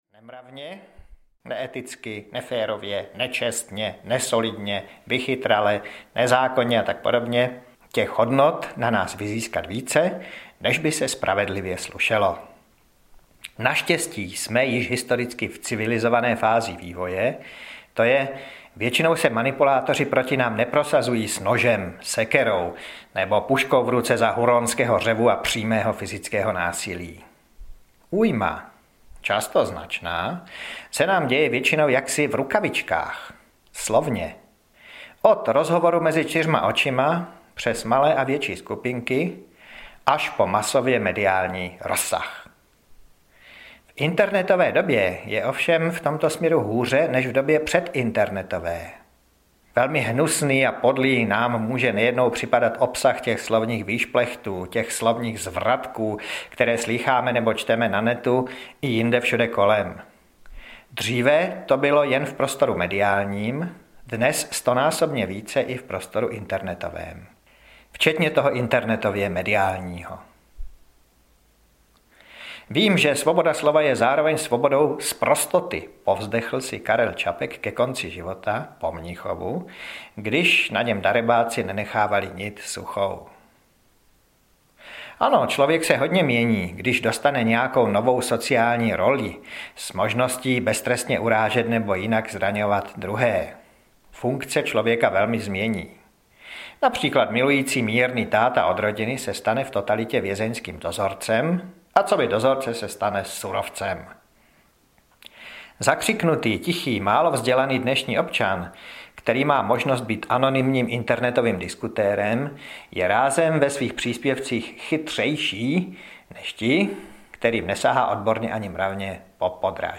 Konec manipulace audiokniha
Ukázka z knihy